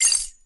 emit_keys_01.ogg